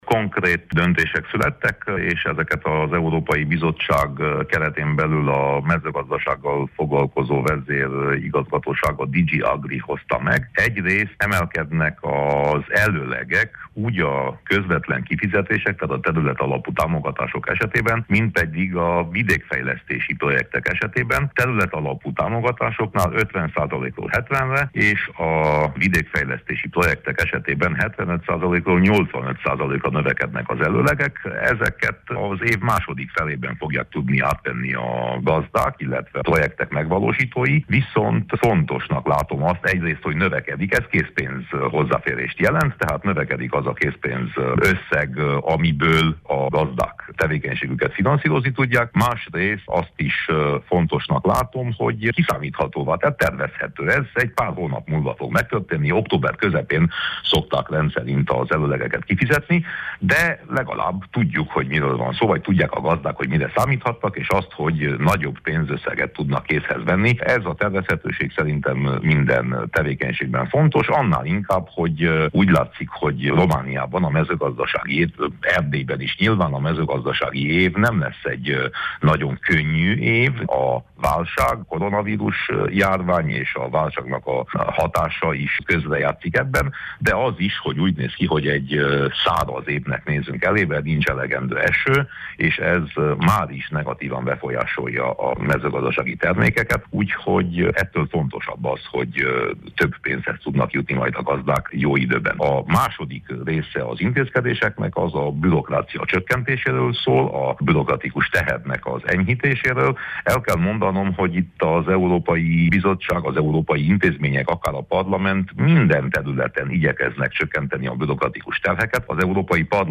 Winkler Gyula EP képviselőt kérdezte